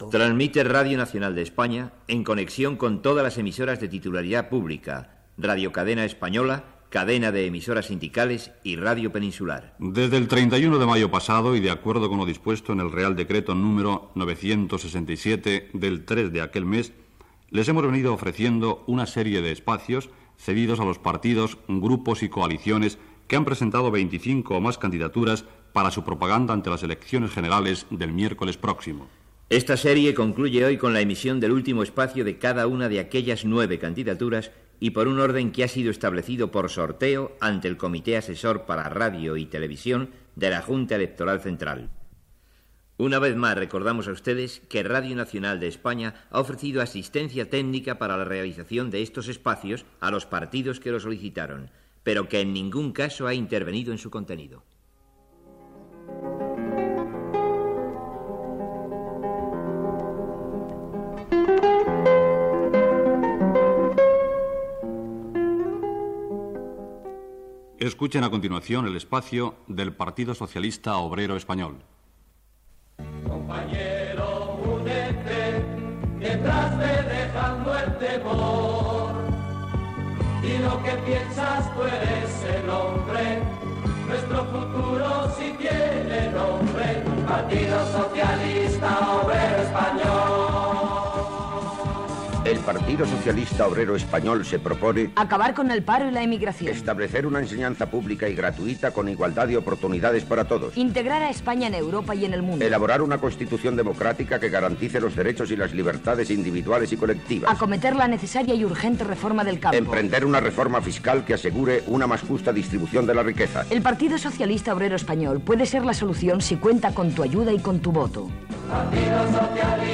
Identificació de RNE, RCE, CES i Radio Peninsular, introducció als espais de propaganda electoral, espai del Partido Socialista Obrero Español (PSOE), preguntes al candidat Felipe González.
Informatiu